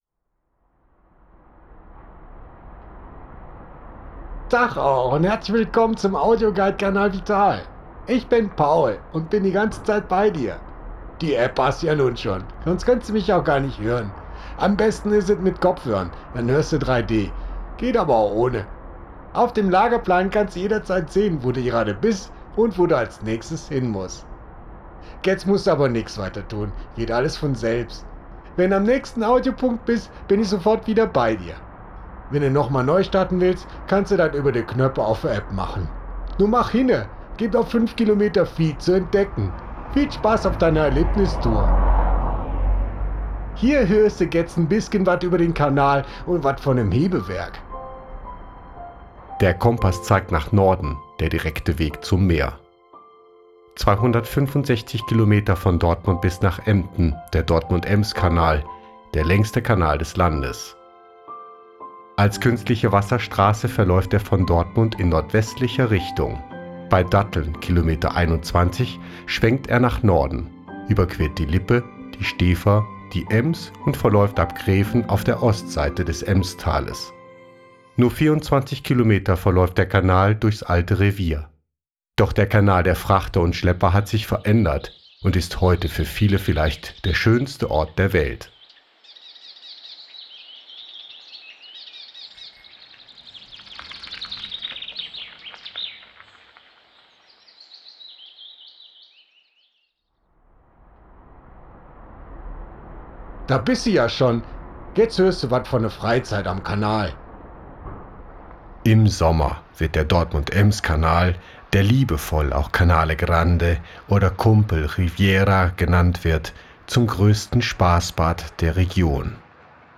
Hier noch ohne die finalen Sprecher
Zusammen mit Originaltönen, Geräuschen und Musik entsteht ein lebendiges Hörerlebnis.
Genau das ermöglicht der Audioguide: Durch die räumliche Anordnung der Klänge entsteht eine dreidimensionale Klangwelt, in die du eintauchen kannst – fast so, als wärst du mitten im Geschehen.
Audioguide-Snippet.wav